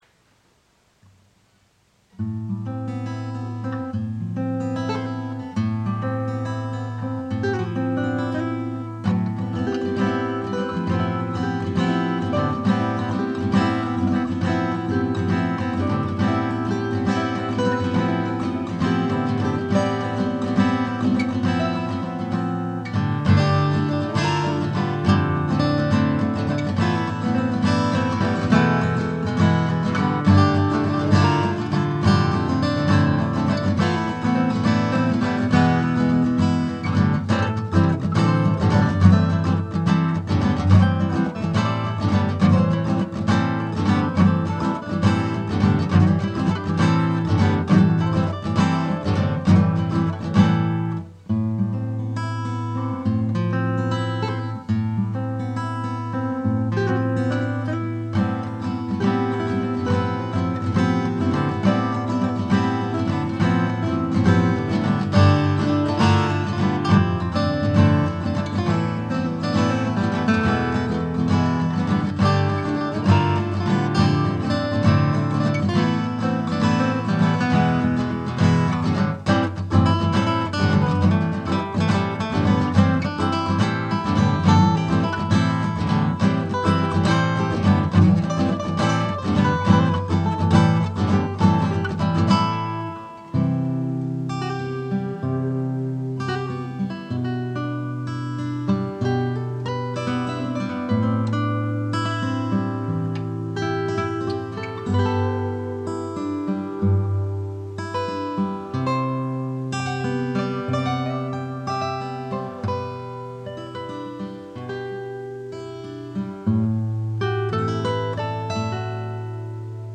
Gitarren